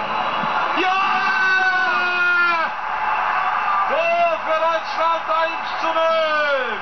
Unten findet Ihr die Reportagen zu acht berühmten Toren, bei denen sich die Kommentatoren besonders ins Zeug gelegt haben, verbunden mit jeweils einer Frage.